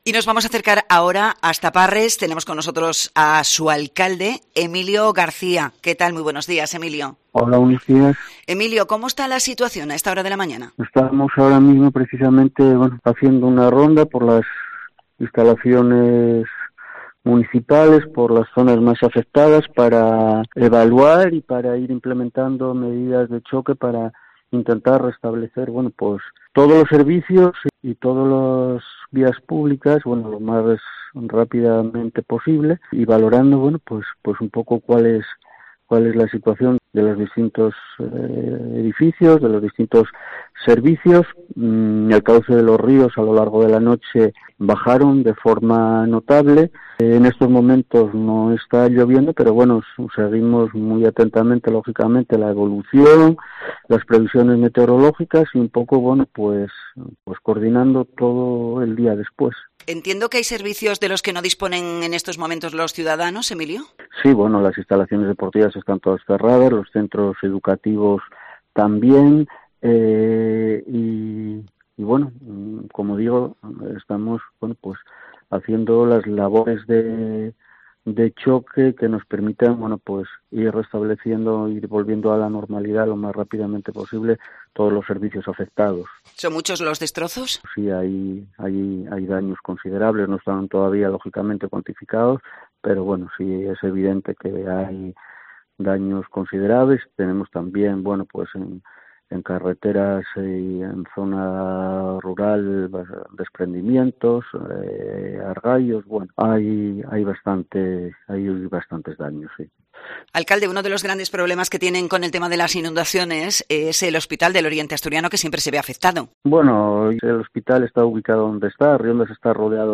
El alcalde, Emilio García Longo, ha hablado en COPE Asturias tras las fuertes lluvias que han anegado varios puntos del concejo, con colegios y el hospital cerrados
Entrevista al alcalde de Parres, Emilio García Longo